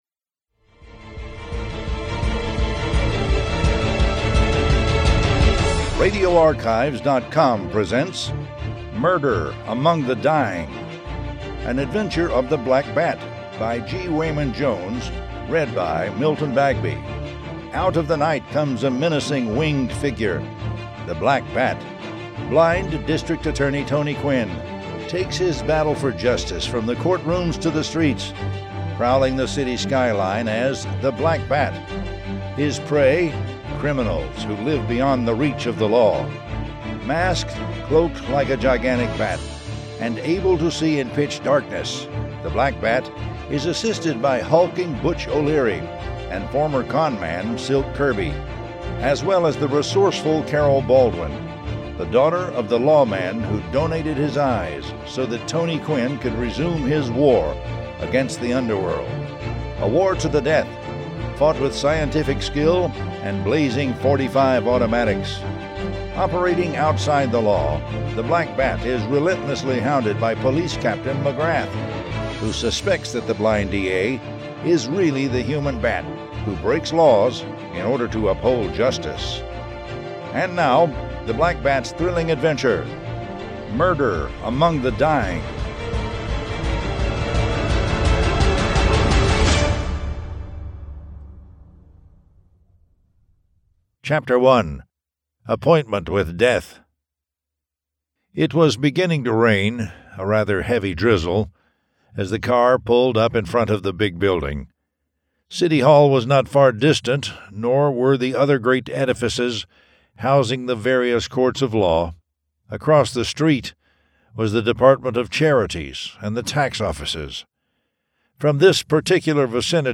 The Black Bat Audiobook #34 Murder Among the Dying